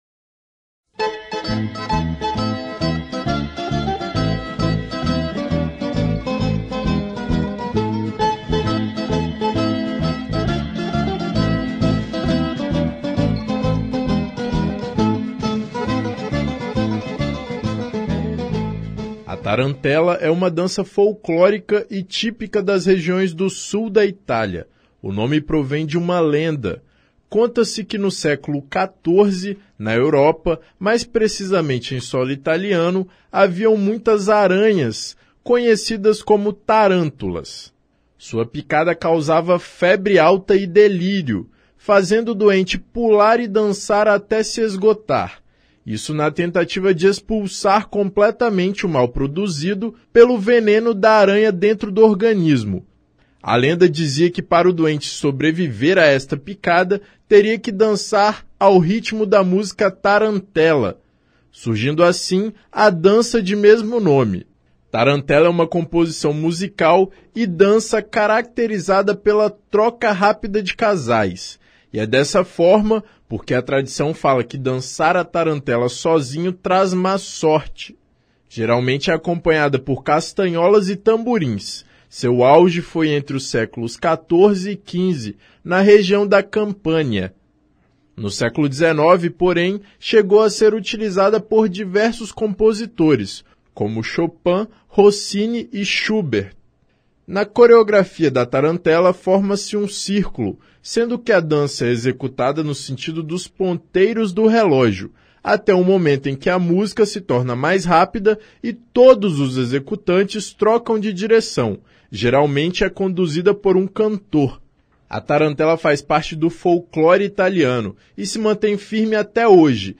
Tarantela | Universitária FM
A Tarantela é uma dança folclórica italiana. Ela é típica das regiões do sul da Itália e surgiu a partir de uma lenda: essa região do país era infestada por Tarântulas, aranhas, e a forma de curar quem fosse picado pelo aracnídeo era pular e dançar.